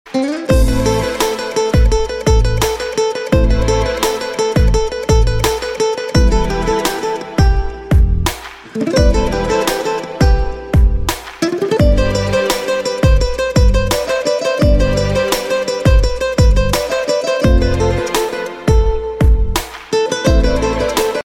آهنگ بی کلام زنگ موبایل عاشقانه لاتی